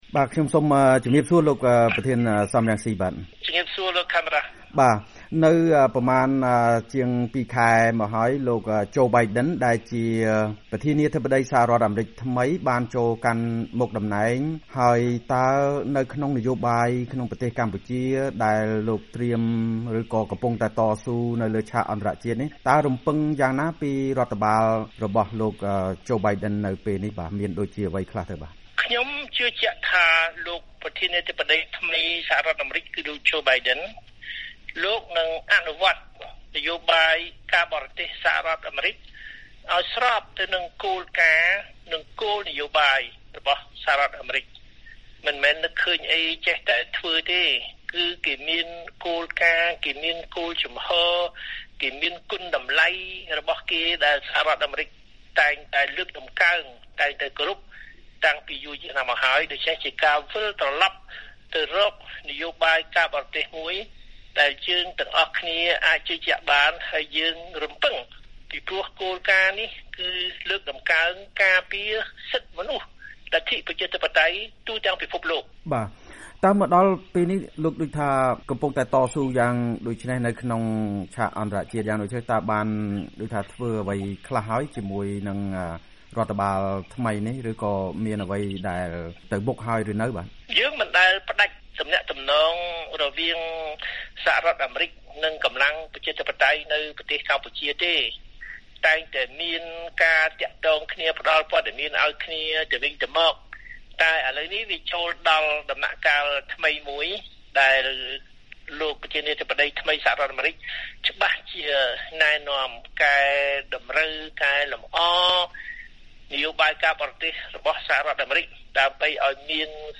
បទសម្ភាសន៍ VOA៖ លោក សម រង្ស៊ី ជឿថាលោក បៃដិន អឺរ៉ុបនិង អ.ស.ប នឹងជួយដោះស្រាយនយោបាយកម្ពុជា